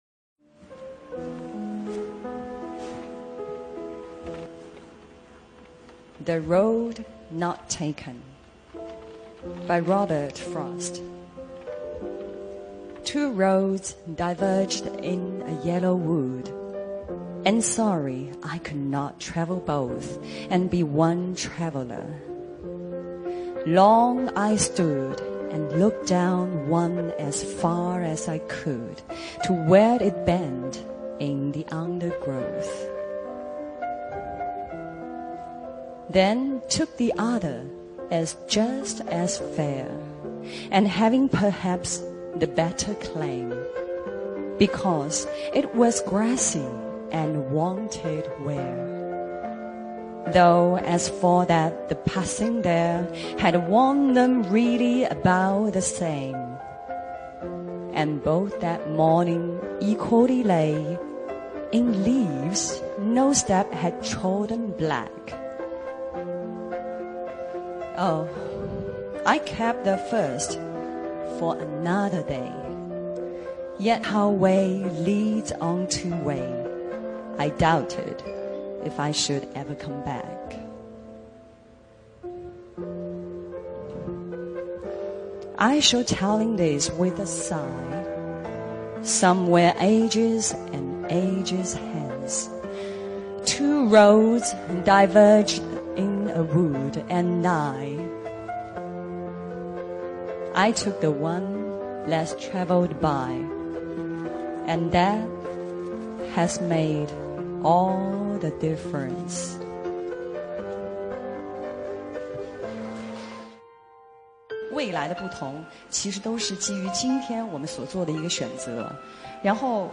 汤唯英语演讲：未选择的路